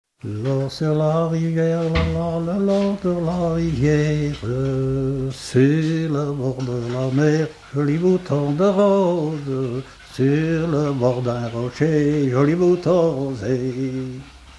Fonction d'après l'informateur gestuel : à marcher ;
Genre laisse
Catégorie Pièce musicale inédite